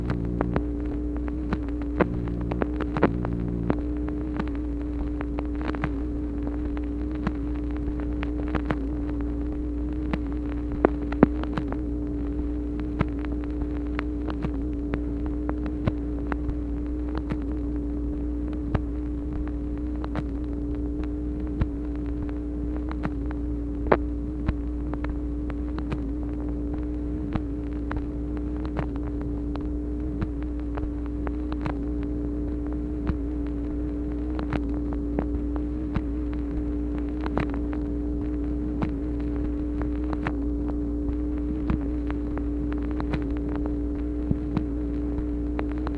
MACHINE NOISE, January 9, 1964
Secret White House Tapes | Lyndon B. Johnson Presidency